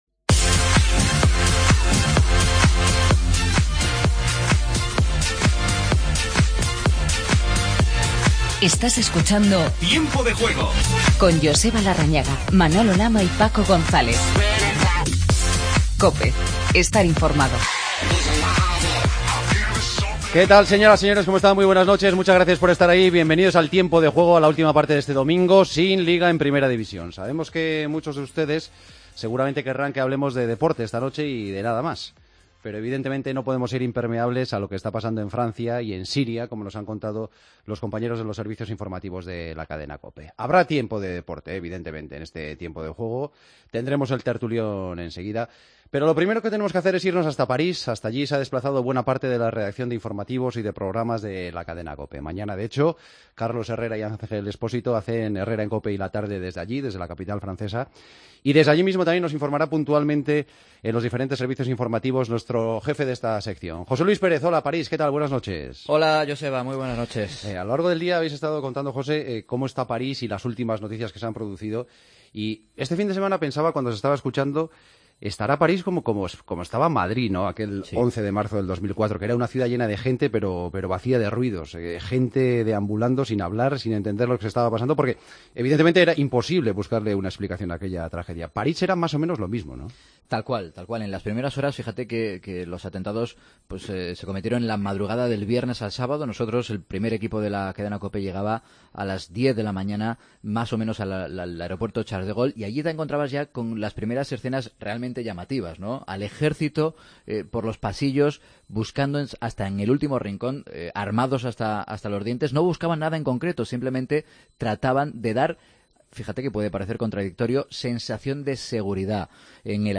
Última hora de los atentados de París. Entrevista a Vicente del Bosque.